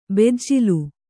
♪ bejjilu